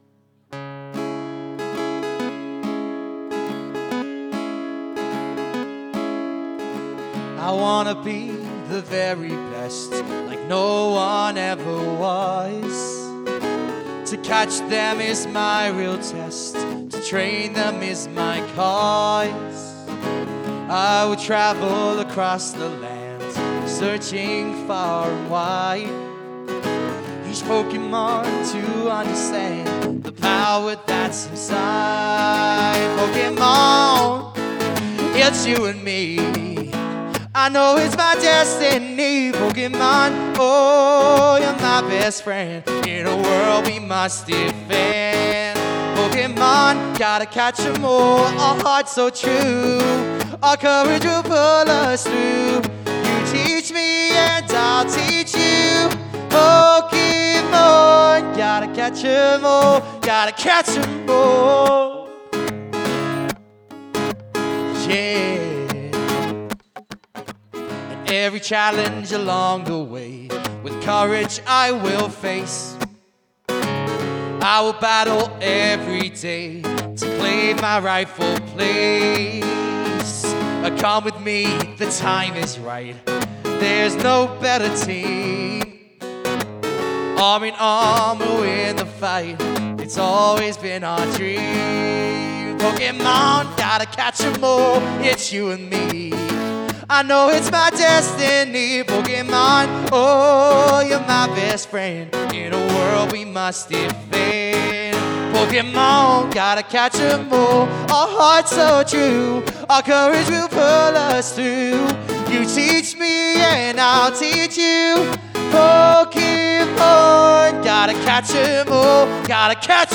acoustic guitar singing